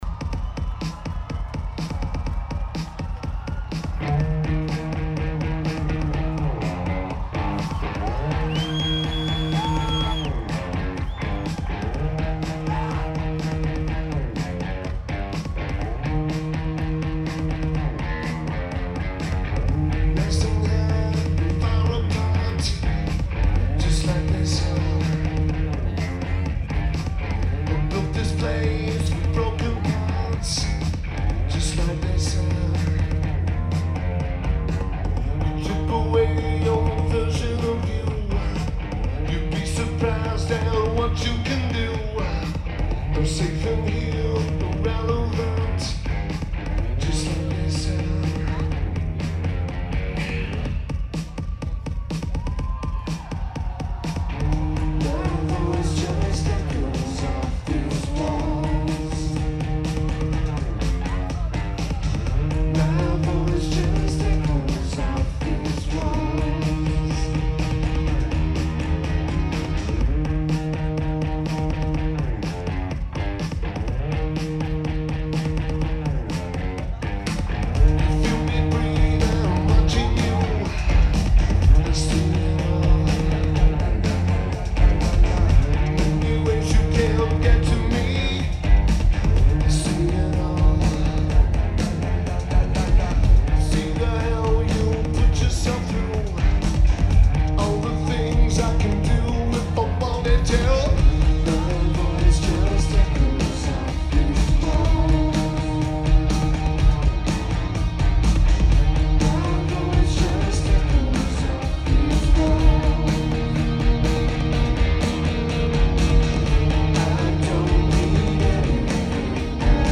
Grand Rapids, MI United States
Lineage: Audio - AUD (DPA 4061 > MPS6030 > Edirol R-09)
Recording is good!